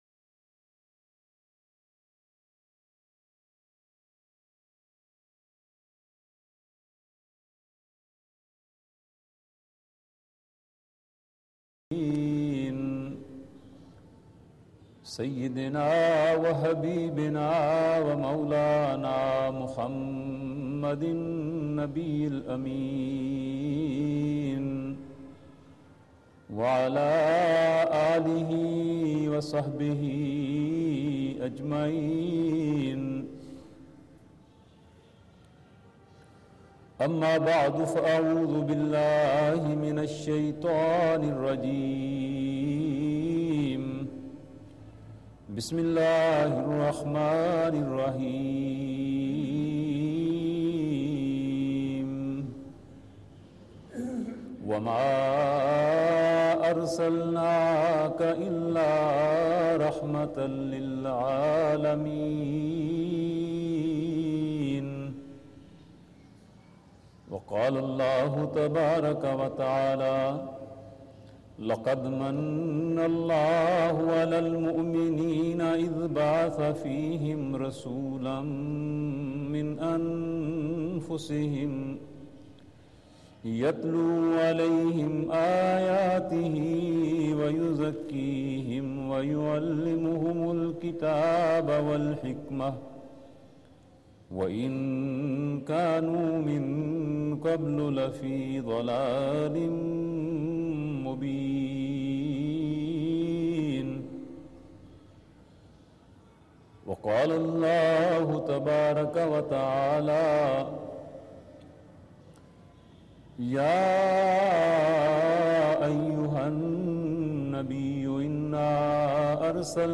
Khutba-Juma
4070_Khutba-Juma.mp3